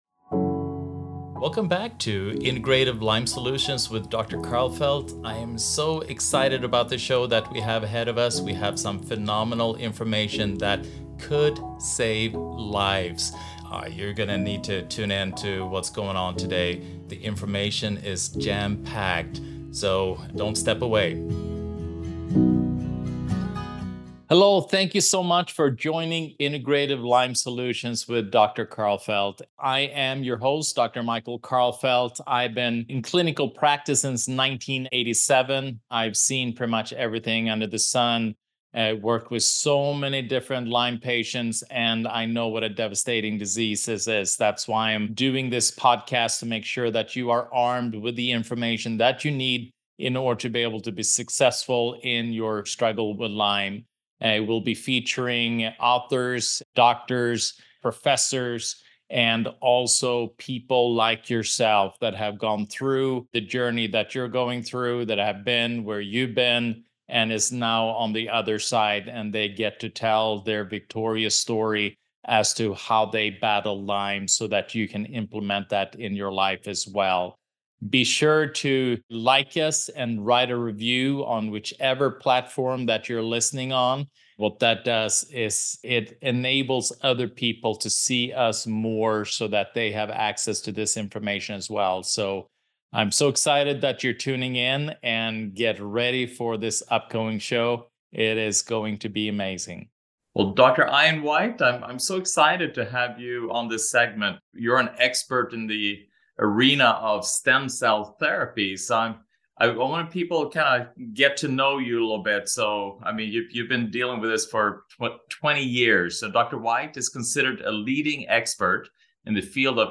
Stem Cell Therapy and the Space Age: A Conversation